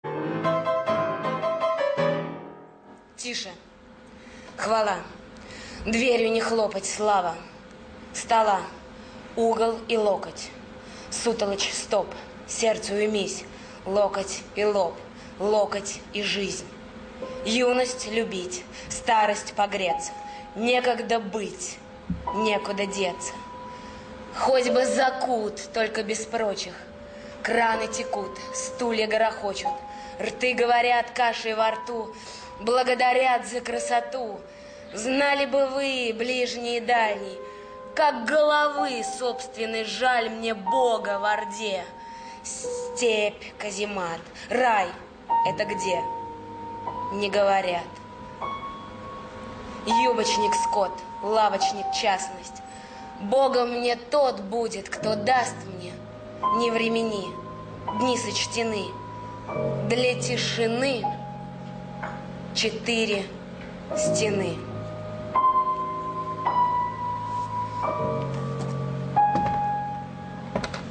1. «Читает Чулпан Хаматова – Тише, хвала Марина Цветаева РадиоСтихи» /
chitaet-chulpan-hamatova-tishe-hvala-marina-tsvetaeva-radiostihi